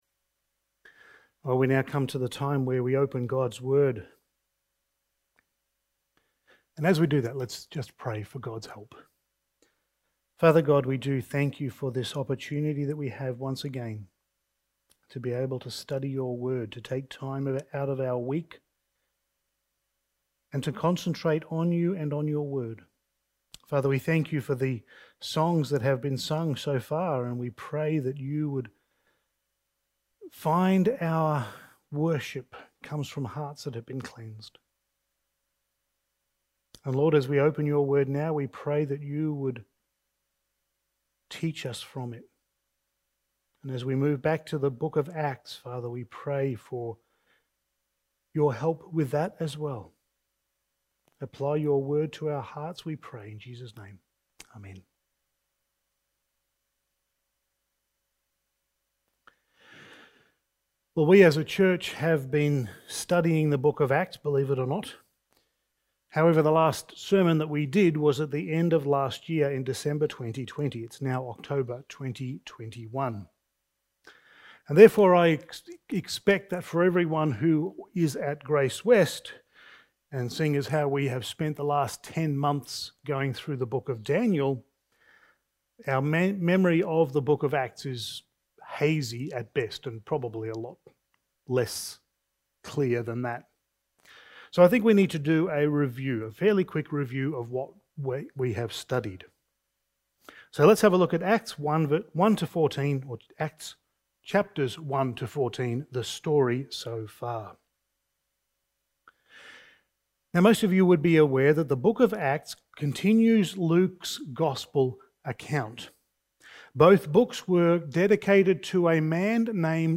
Passage: Acts 15:1-21 Service Type: Sunday Morning